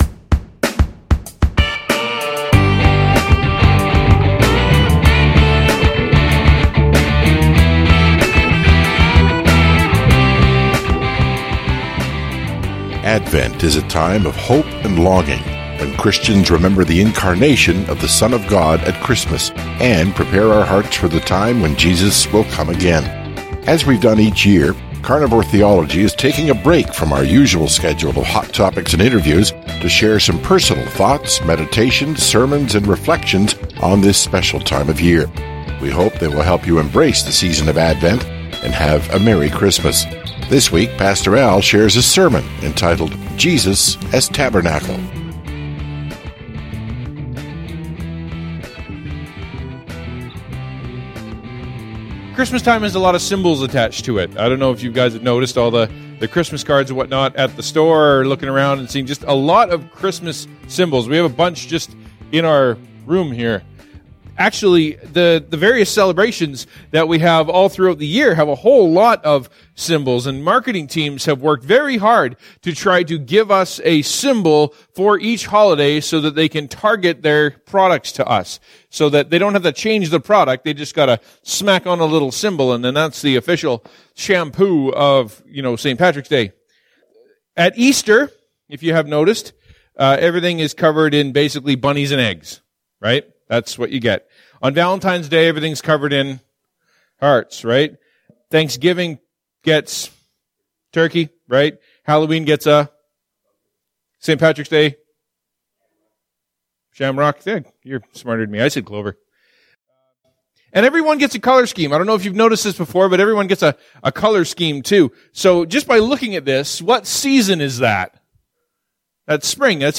Advent Sermon: Jesus as Tabernacle